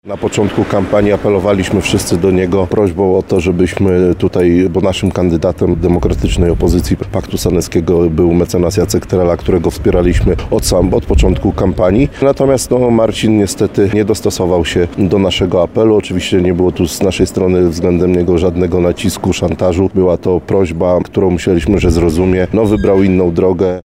Myślę, że Marcin mógł się tego spodziewać – wyjaśnia Piotr Choduń, przewodniczący Klubu Radnych Prezydenta Krzysztofa Żuka